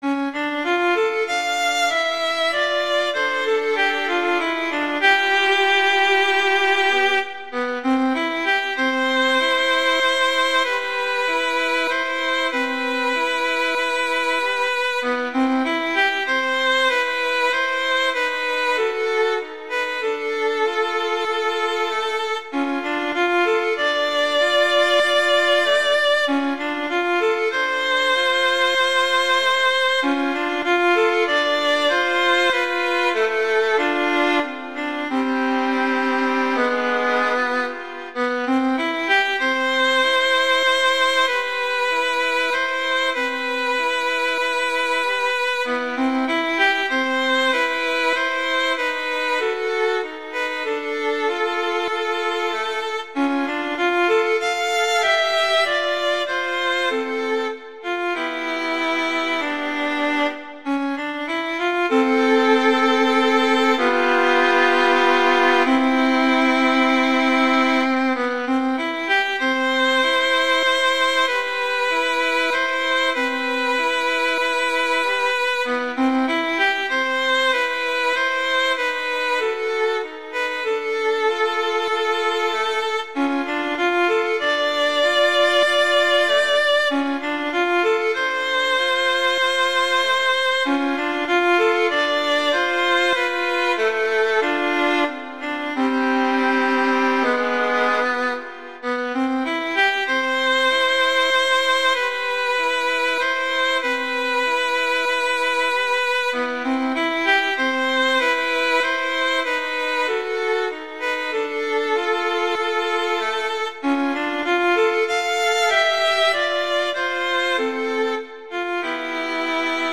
arrangement for two violins
jazz, traditional, wedding, standards, festival, love
C major
♩=96 BPM